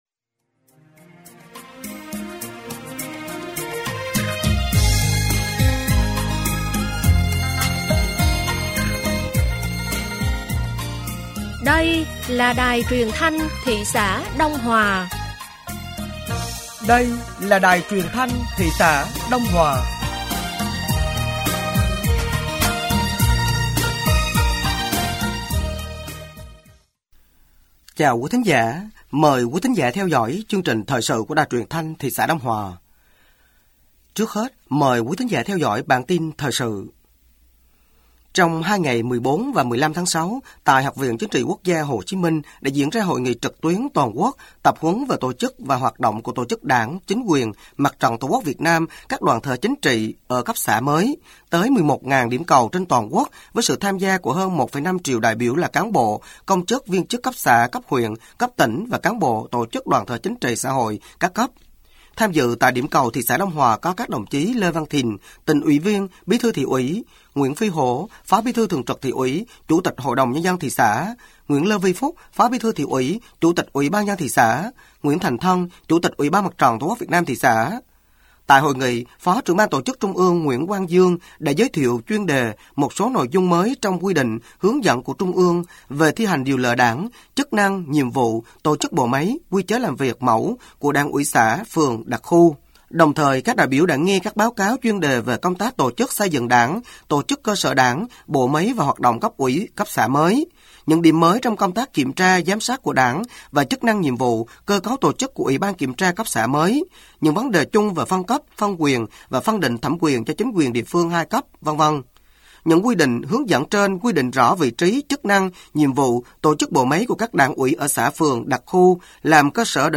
Thời sự tối ngày 16 sáng ngày 17 tháng 6 năm 2025